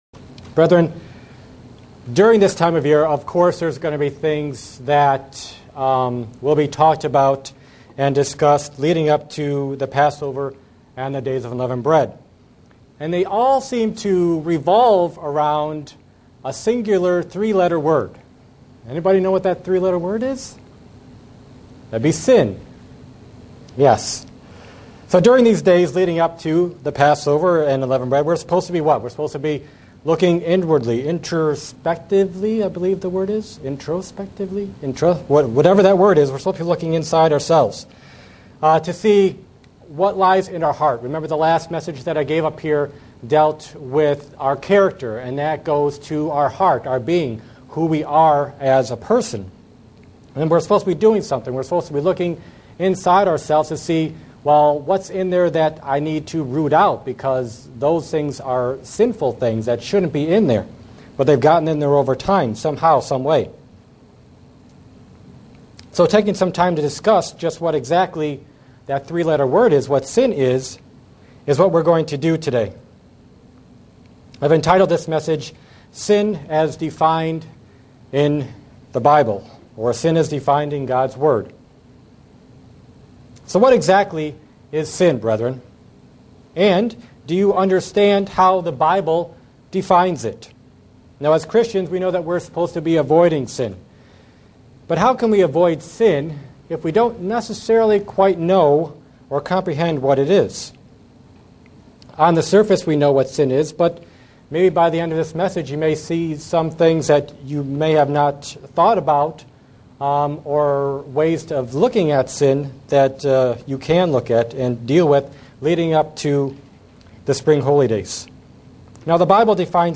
Print Sin can be defined in many ways but the Bible has a certain precise definition. sermons Studying the bible?